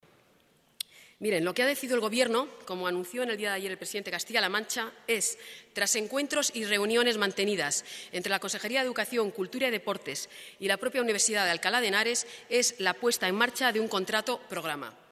Consejera de Educación: comparecencia en el Pleno de las Cortes II